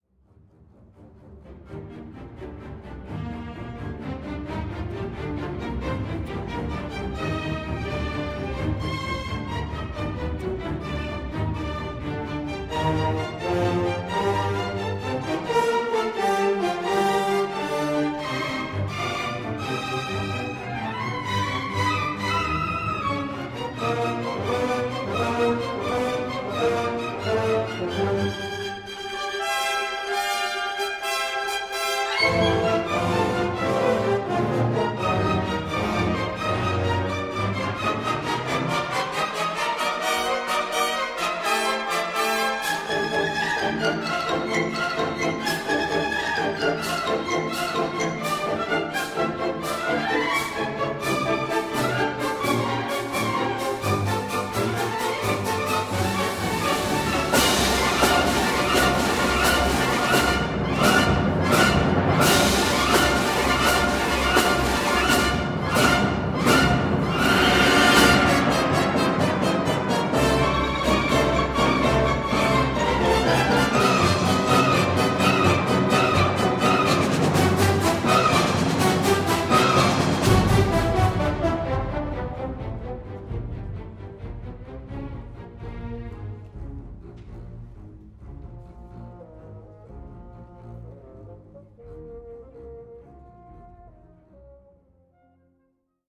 Sinfonie_Nr_6_h-Moll_op_54_-_Presto_-_Dmitri_Schostakowitsch.mp3